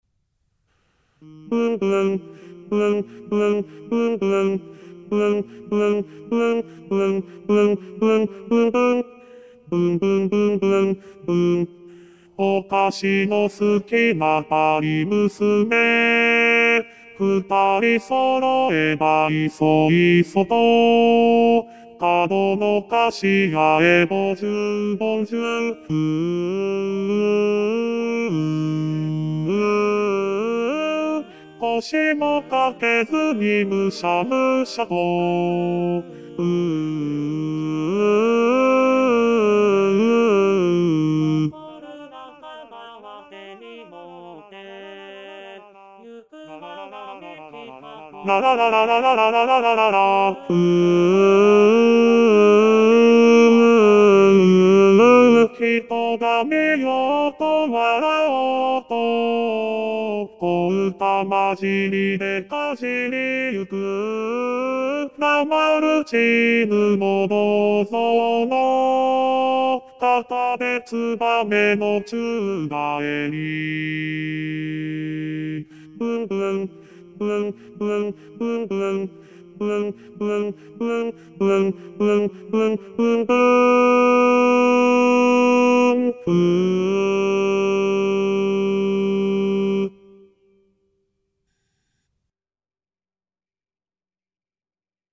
★第１２回定期演奏会　演奏曲　パート別音取り用 　機械音声(ピアノ伴奏希望はｽｺｱｰﾌﾟﾚｱｰsdxで練習して下さい)